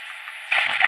radio_off.ogg